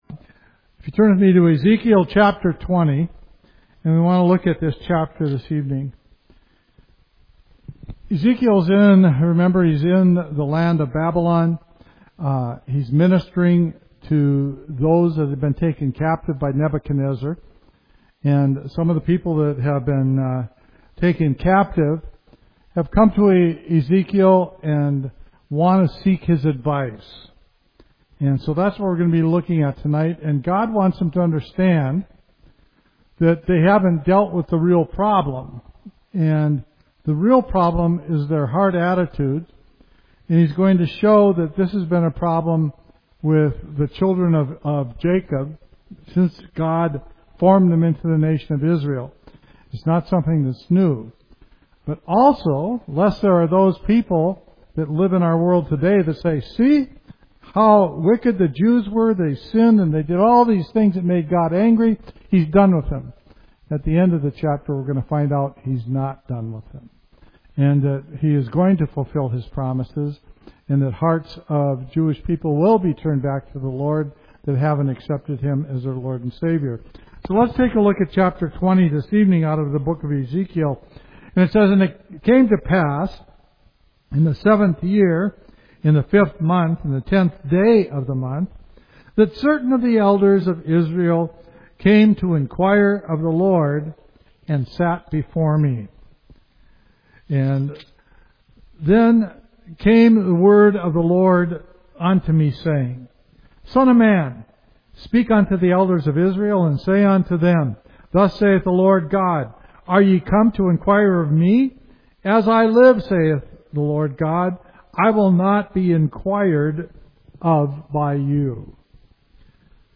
Explore Ezekiel 20’s powerful message of Israel’s rebellion, God’s mercy, and His faithfulness to His name. A deep, verse-by-verse Bible study.